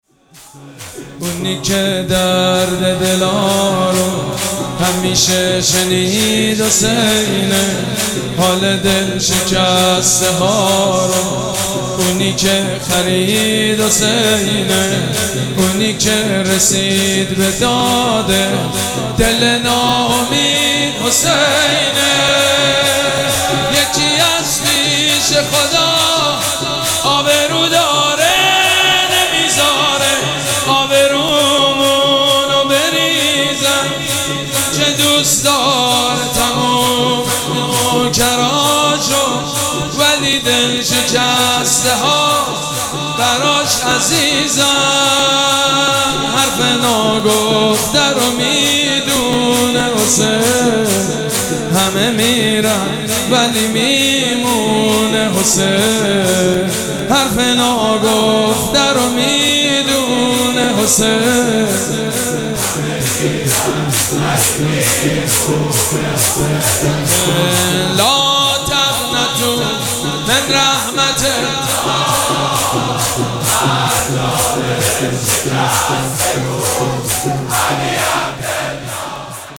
مراسم عزاداری شب نهم محرم الحرام ۱۴۴۷
شور
مداح